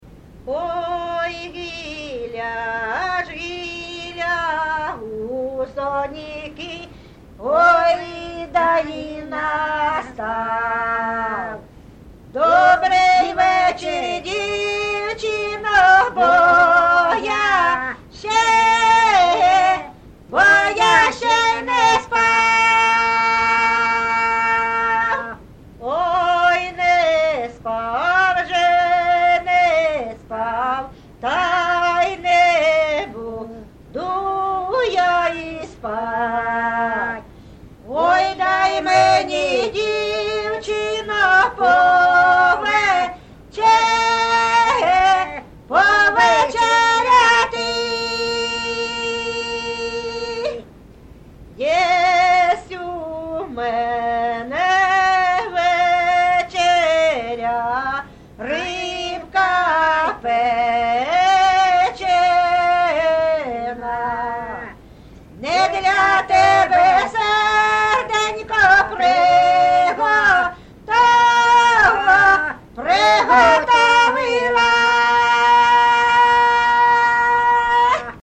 ЖанрПісні з особистого та родинного життя
Місце записус. Закітне, Краснолиманський (Краматорський) район, Донецька обл., Україна, Слобожанщина